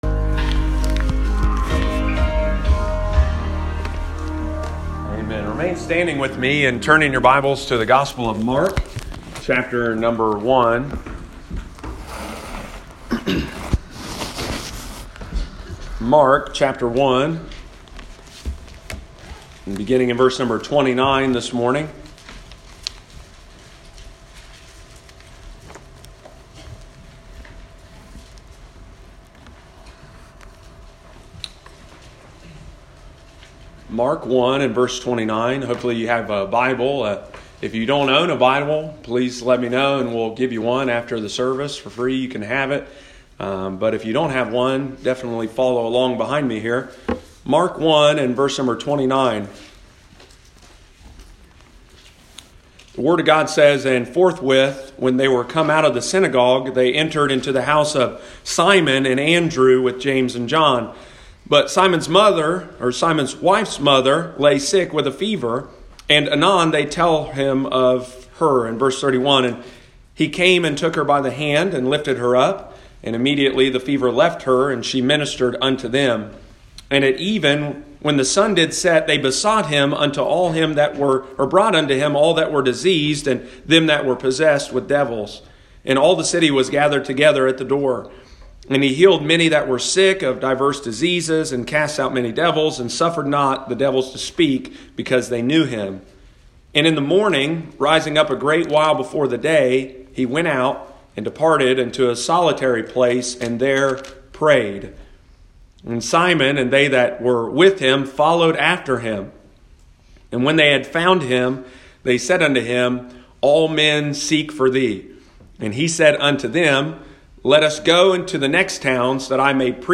focuses on prayer during the morning service in the 2020 series “Continue.”